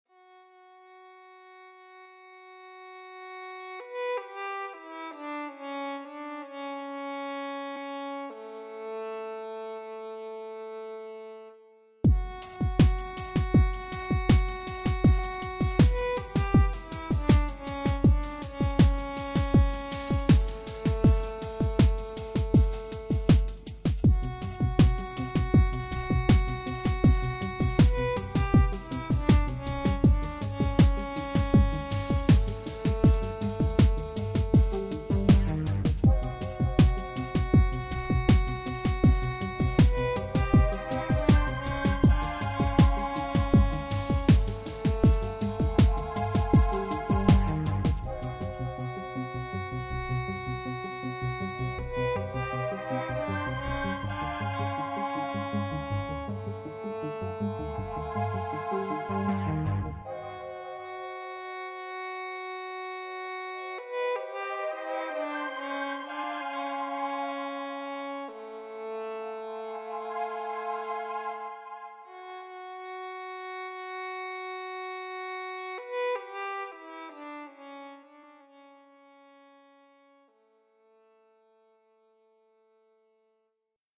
JSBach-Air-Violin-Chalenge-online-audio-converter.com_.mp3